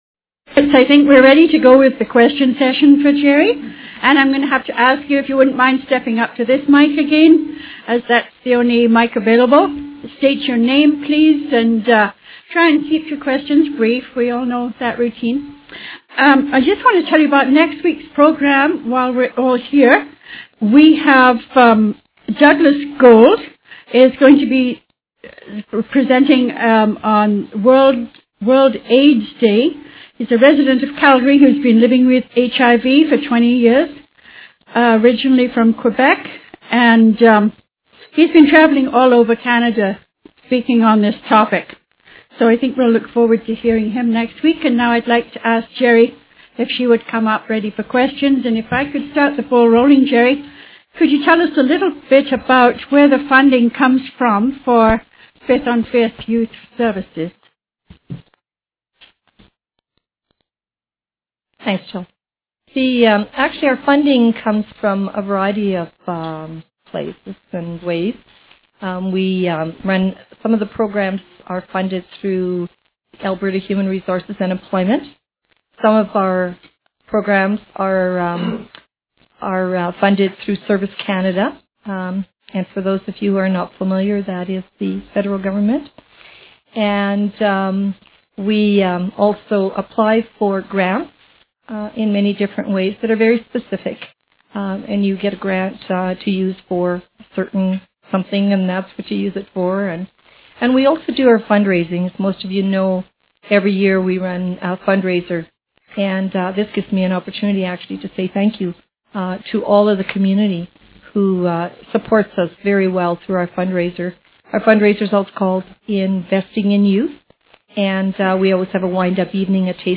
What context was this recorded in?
Location: Sven Ericksen''s Family Restaurant, 1715 Mayor Magrath Drive S. Time: 12:00 Noon to 1:30 p.m. / Cost: $8.00 includes lunch Download the Audio of this session.